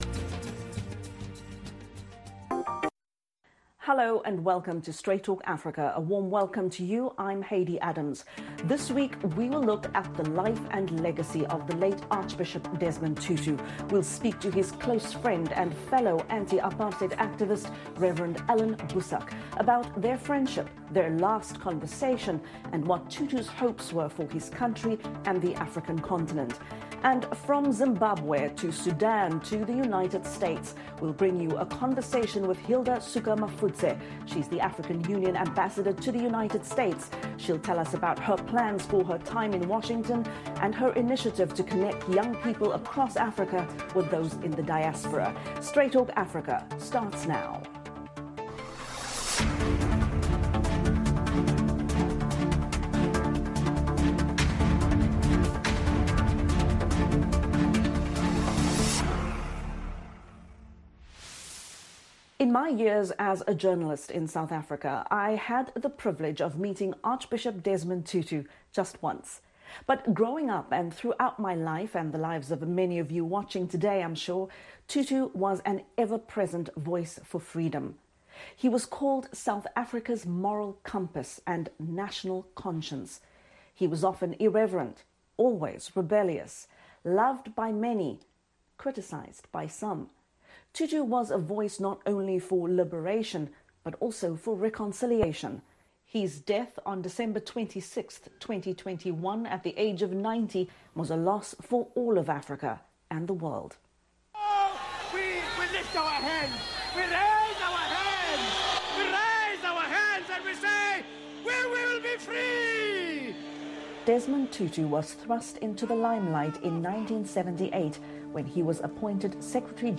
The Life and Legacy of Desmond Tutu & Interview With New AU Ambassador to US [simulcast]
Plus, Africa sends a new envoy to Washington. Guests include Allan Boesak, a South African cleric, politician and anti-apartheid activist and Hilda Suka-Mafudze, the Africa Union ambassador to the U.S.